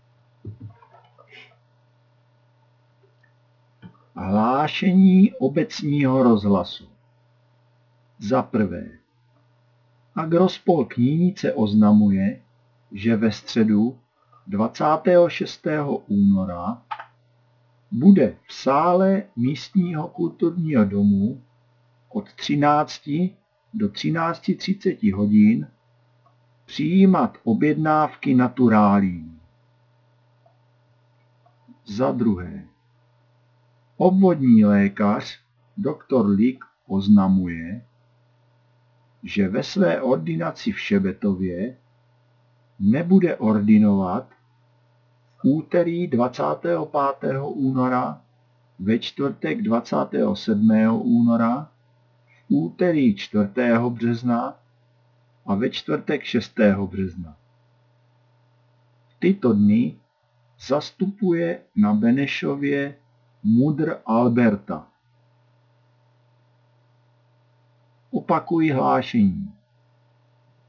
Hlášení 24.2.2025